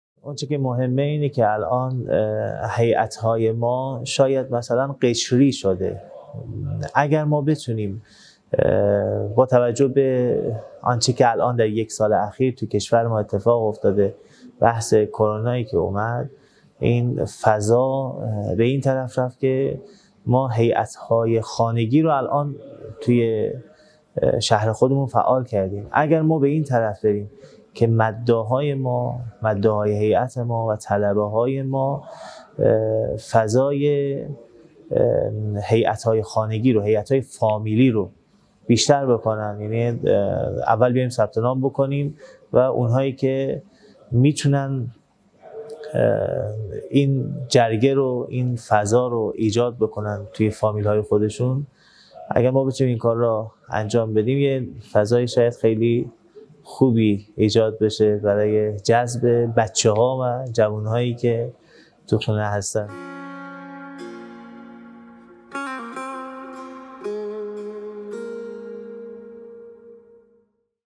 گفتگویی
در اولین نشست صمیمانه جمعی از منبری‌های جوان هیأت‌های کشور